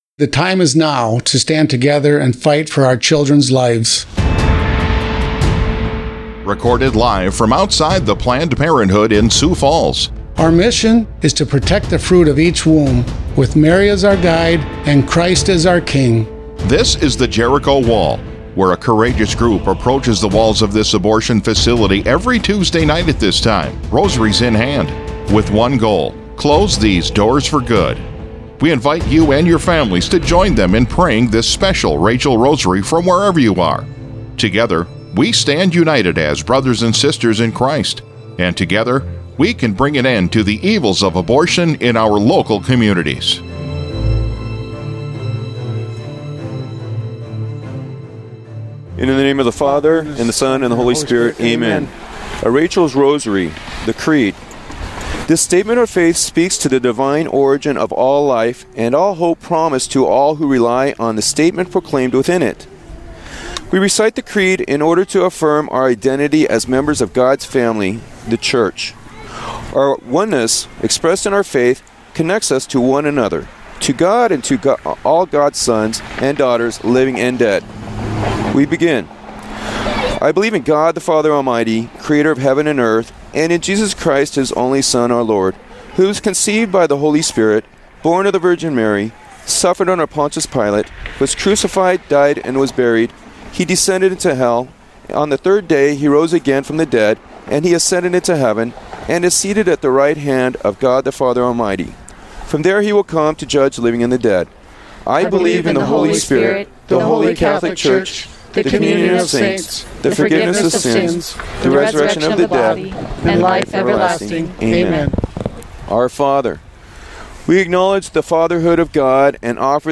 The Jericho Wall has a mission to defend the fruit of each womb with Mary as our Guide and Christ as our King. We pray the Most Holy Rosary every Tuesday at 7 p.m. CT at the Planned Parenthood in Sioux Falls.